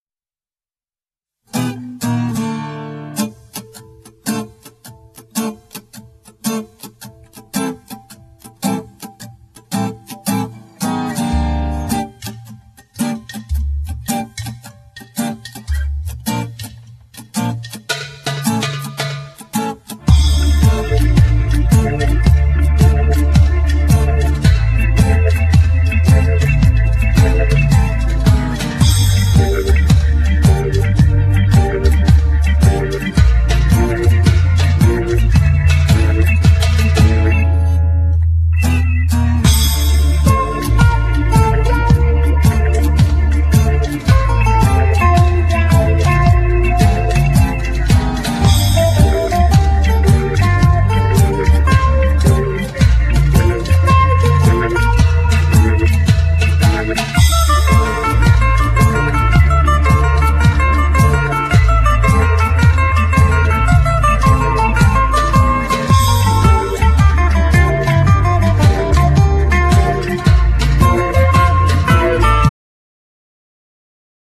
Genere : Etno music